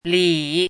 chinese-voice - 汉字语音库
li3.mp3